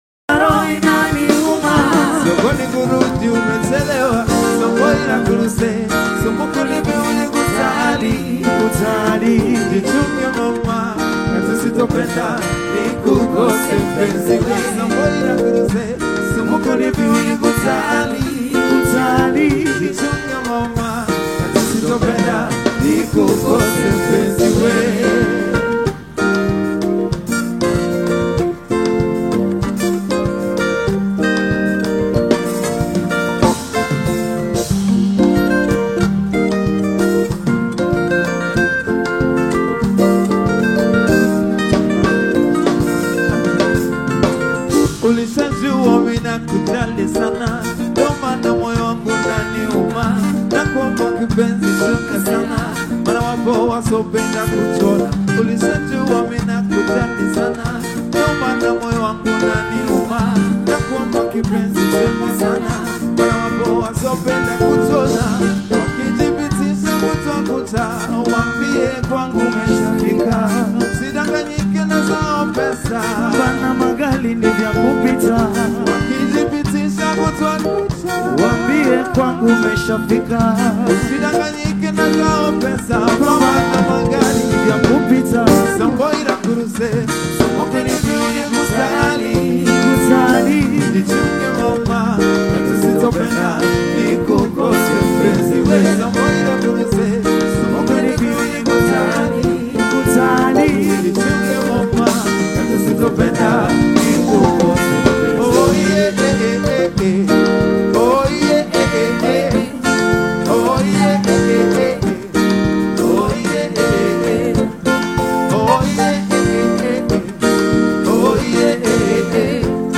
live performance single